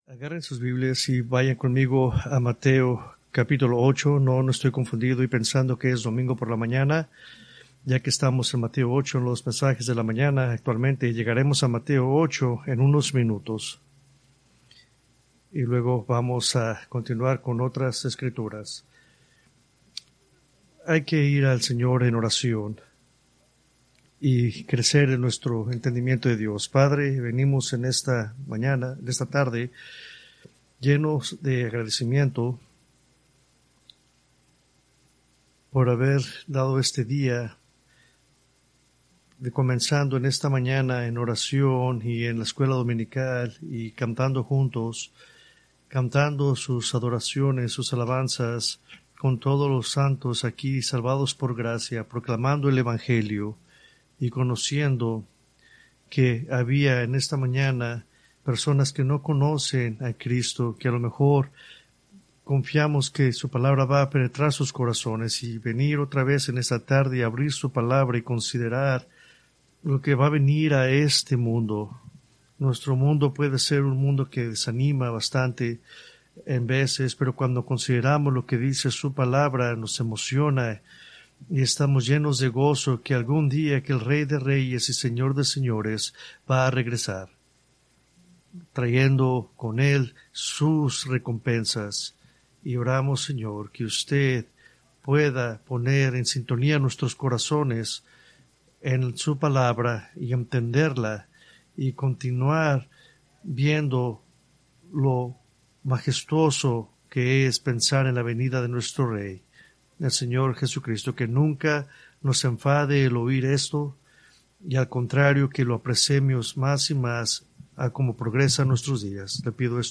Preached October 13, 2024 from Escrituras seleccionadas